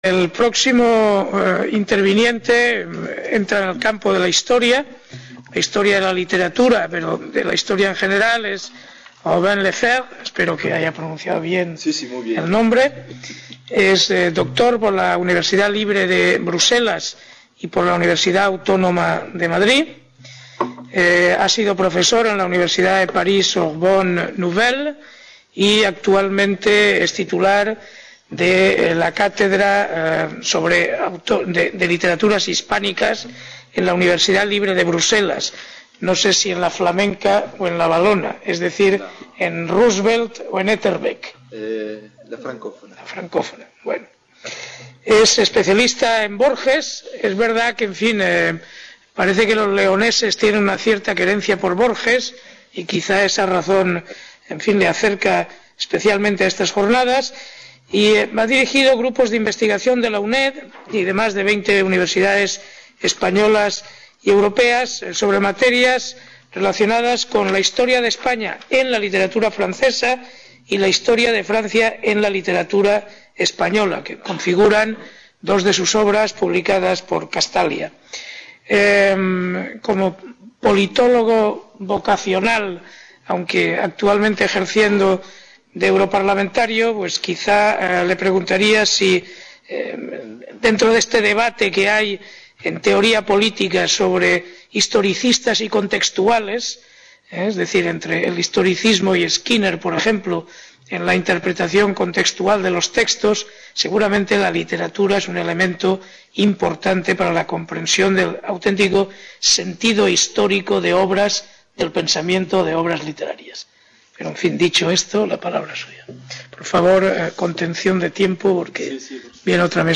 MODERADOR: Enrique Guerrero Salom, Diputado del Parlamento Europeo
Reunion, debate, coloquio...